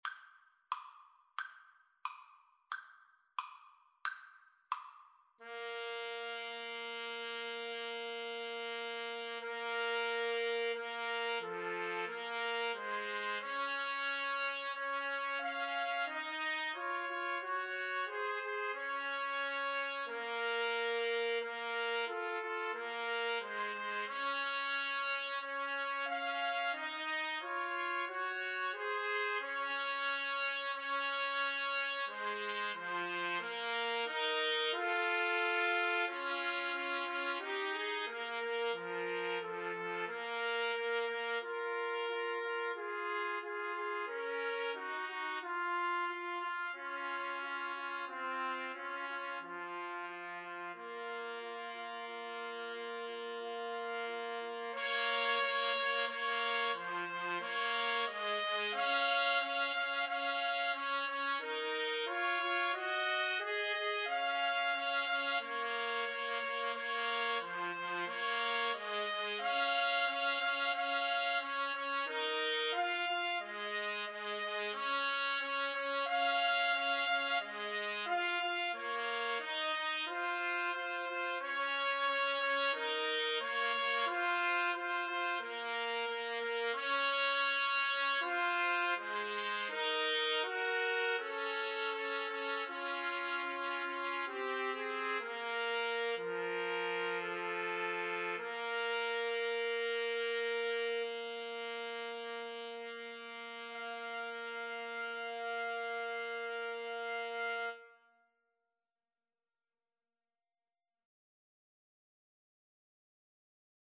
Trumpet 1Trumpet 2Trumpet 3
2/4 (View more 2/4 Music)
=90 Allegretto, ma un poco lento
Classical (View more Classical Trumpet Trio Music)